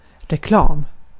Uttal
Uttal Okänd accent: IPA: /rɛˈklɑːm/ Ordet hittades på dessa språk: svenska Översättning 1. ilan 2. duyuru 3. reklam Artikel: en .